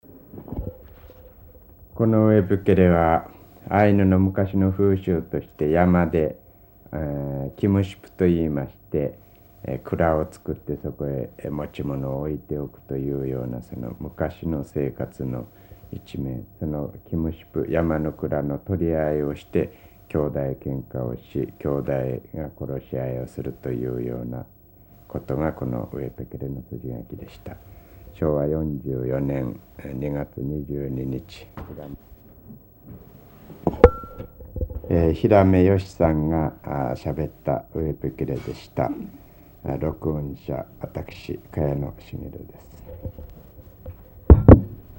[19-6 解説 commentary]【日本語】0:44